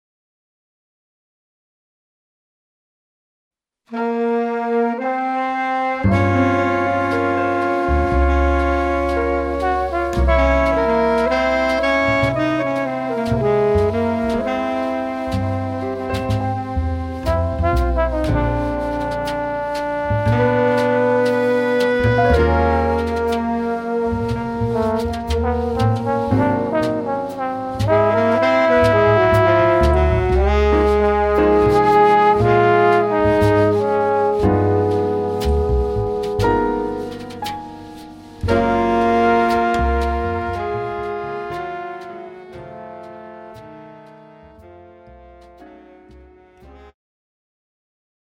The Best In British Jazz
Recorded at Clowns Pocket Studios 2012